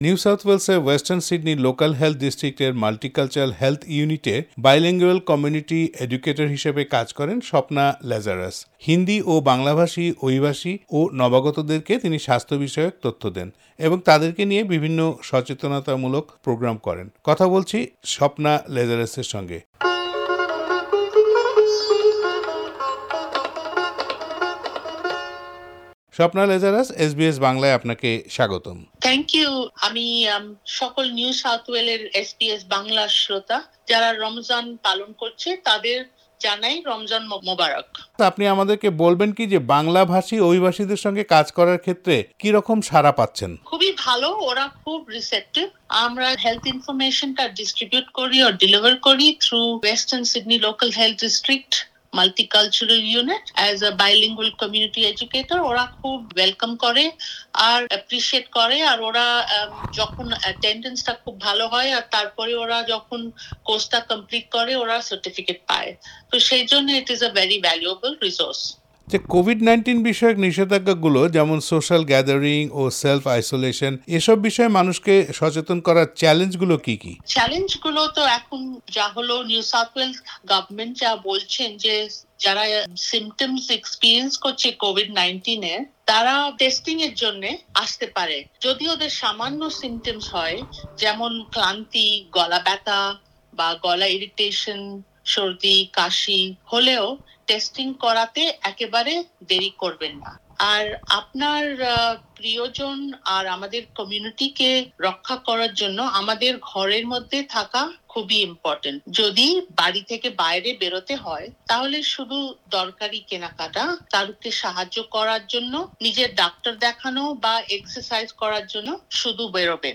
সাক্ষৎকারটি শুনতে উপরের অডিও প্লেয়ারের লিংকটিতে ক্লিক করুন।